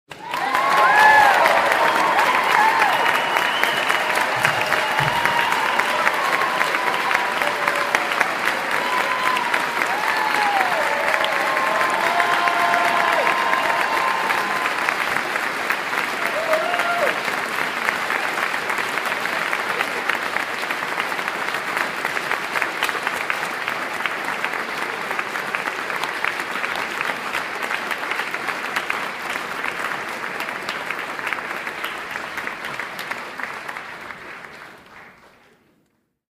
Скачать. Оповещение СМС. Аплодисменты - овация. mp3 звук
Категория: Живые звуки, имитация